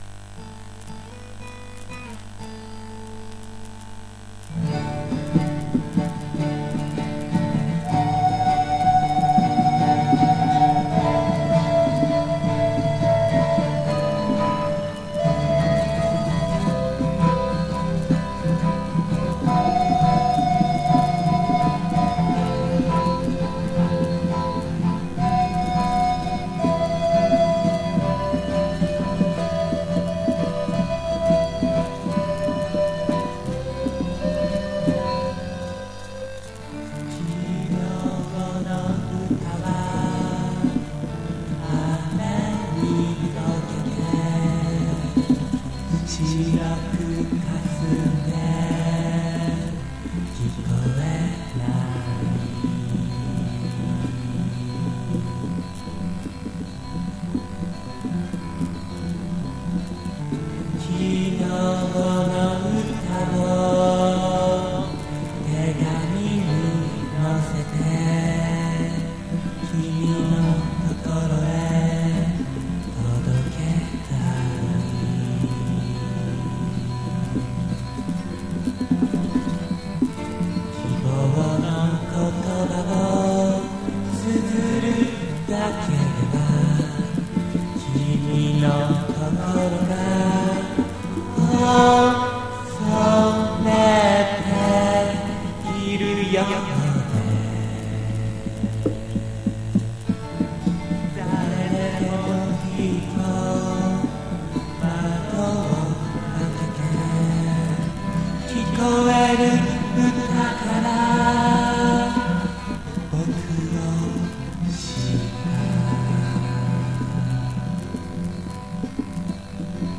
※レコードの試聴はノイズが入ります。